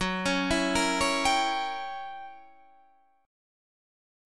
Gb7b9 chord